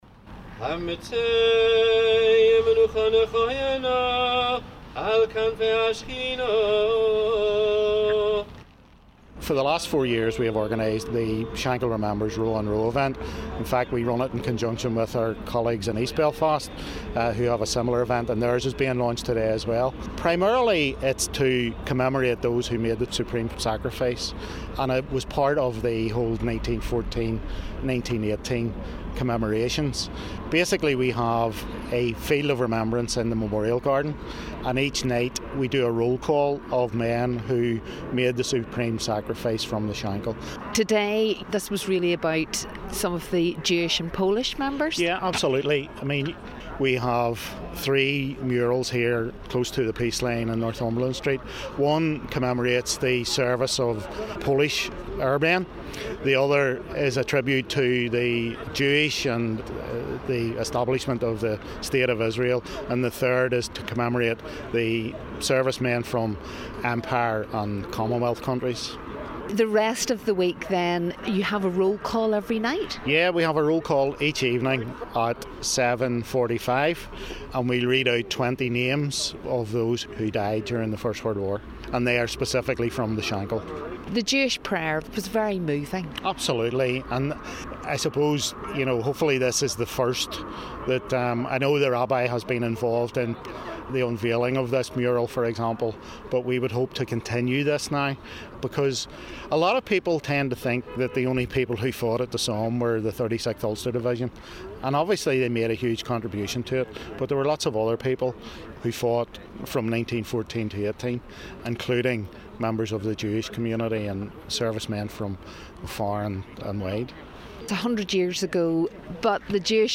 went along to the launch of the Shankill community commemoration event, where wreaths were laid at the Jewish, Polish and Empire murals, just feet away from the peace line in Belfast.